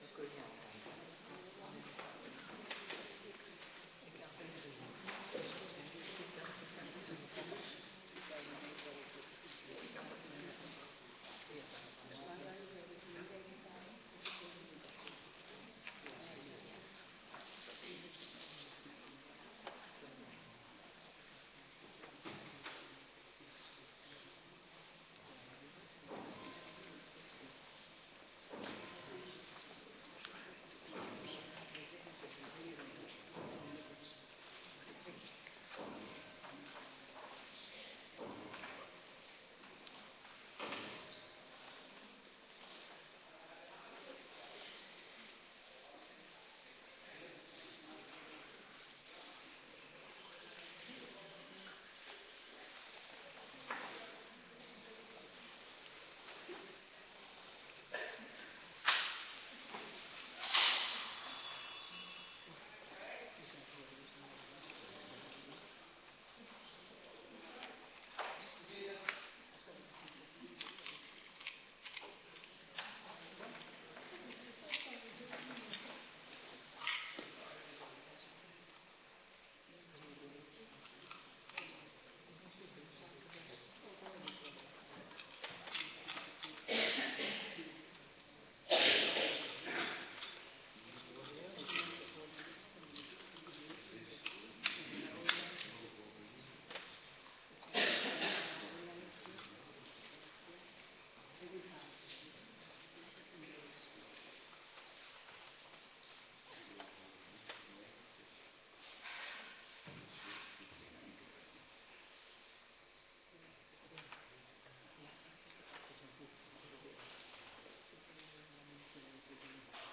Adventkerk Woensdag week 14
19:15 Adventkerk Woensdag week 14 DOWNLOAD VIDEO LUISTER NAAR MP3 "Stille week" Avondgebed Onderwerp “Jezus’ zalving” Collecte